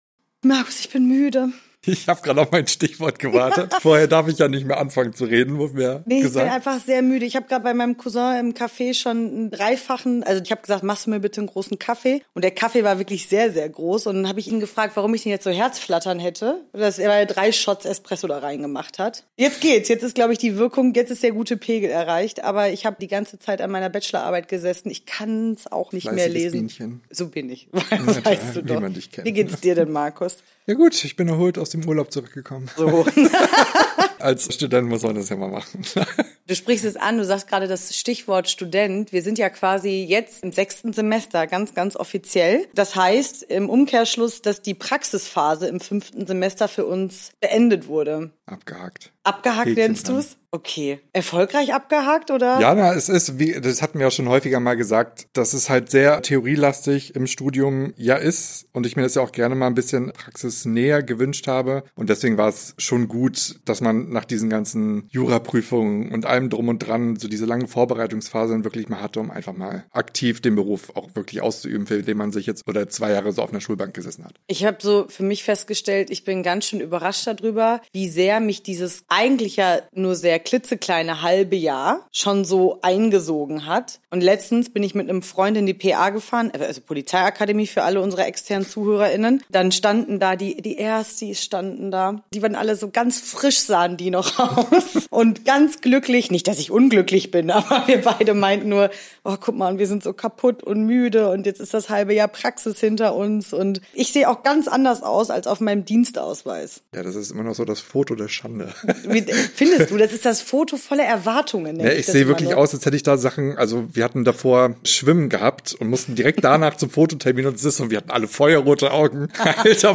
Unsere vier Anwärter:innen erzählen von ihren Erlebnissen im fünften Semester. Es wird transparent und nachdenklich reflektiert und es wird Kritik geäußert.